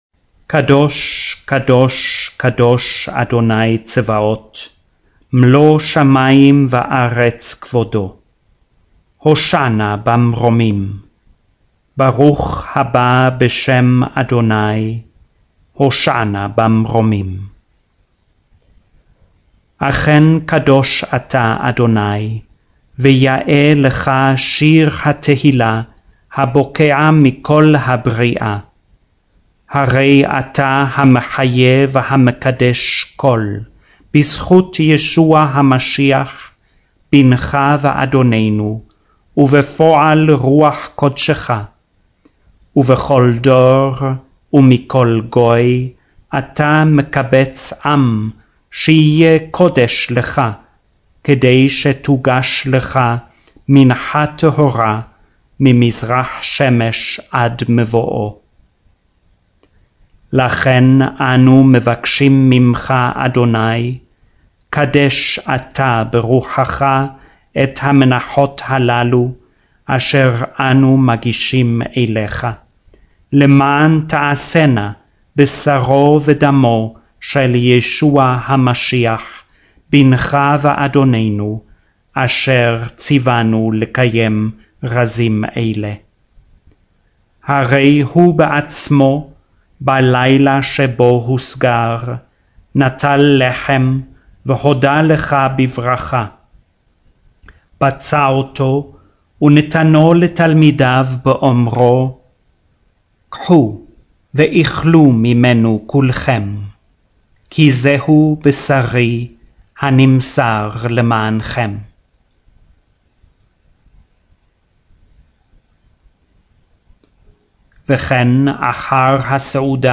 il testo della liturgia cattolica romana letta lentamente in ebraico
10- Appendix- Eucharistic Prayer 3.mp3